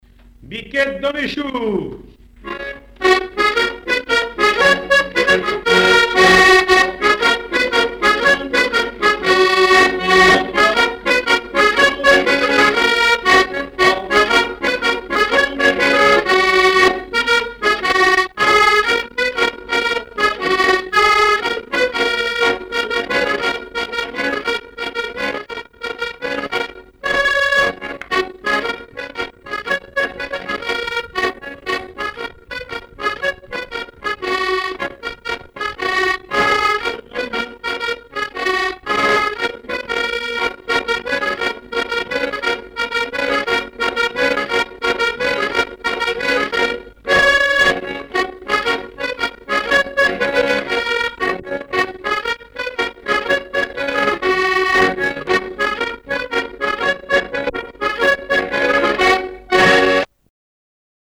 danse : ronde
Répertoire du musicien sur accordéon chromatique
Pièce musicale inédite